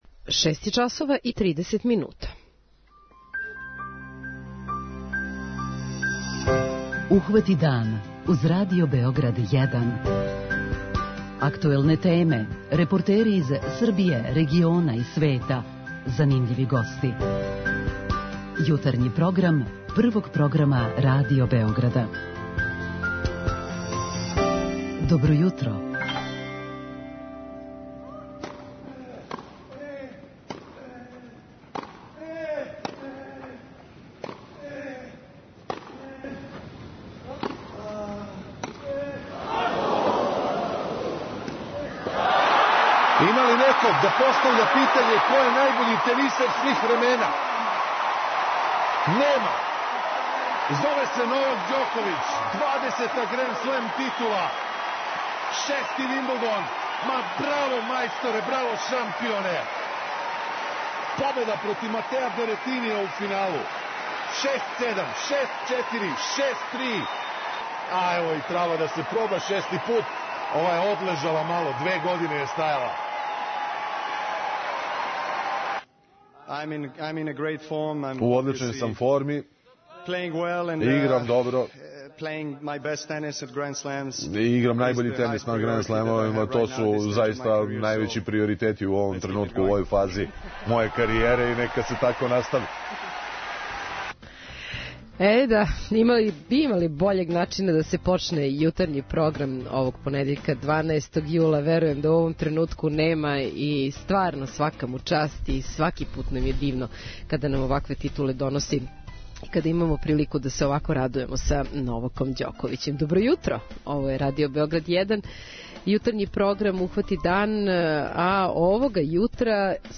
Овога јутра будимо вас из Шапца, града првина - први прозор, прва апотека, први клавир, прва вожња фијакером, прва апотека...Дугачак је низ података и догађаја који заслужују да буду споменути када је овај град у Мачви у питању.
Јутарњи програм Радио Београда 1!